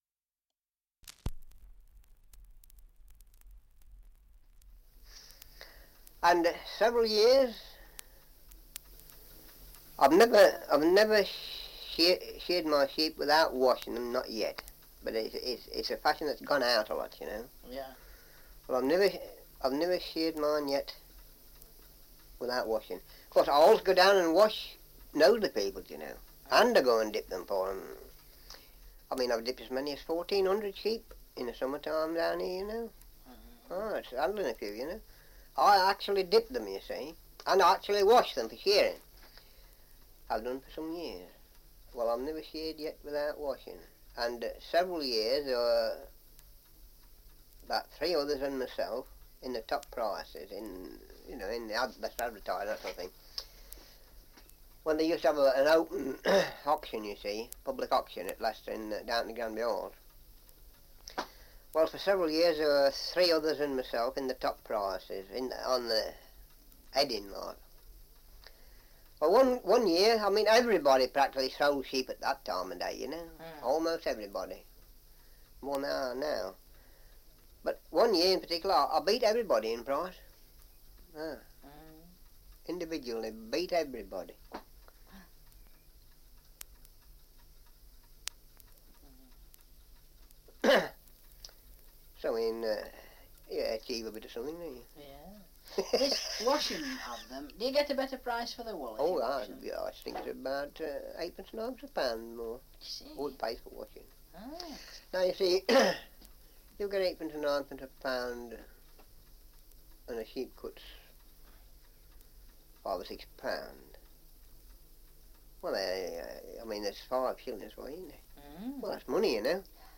Survey of English Dialects recording in Goadby, Leicestershire
78 r.p.m., cellulose nitrate on aluminium